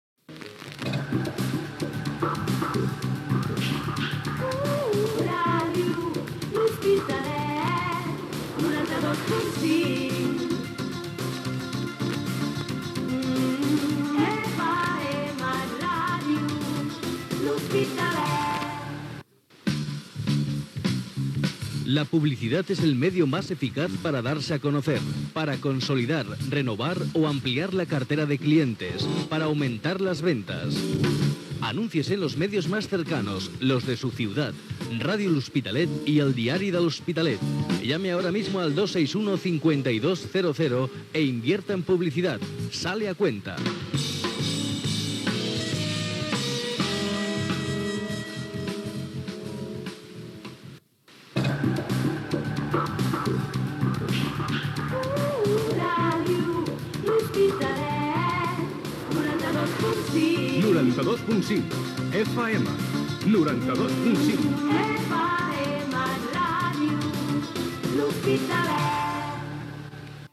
Indicatiu de l'emissora, anunci per anunciar-se a Ràdio L'Hospitalet, indicatiu
FM